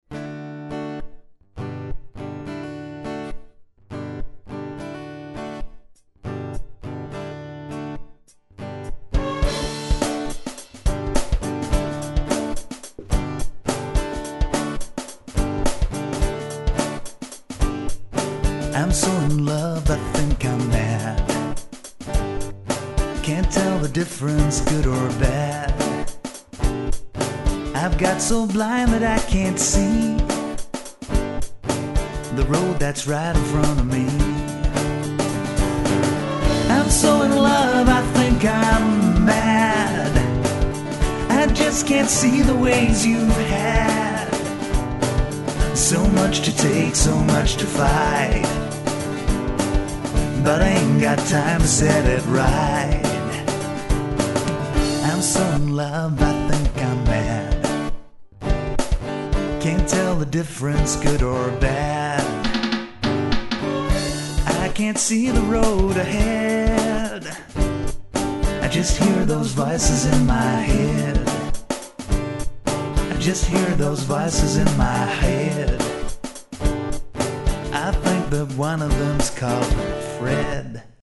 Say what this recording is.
Genre: Synthpop